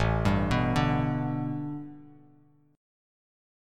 A9sus4 chord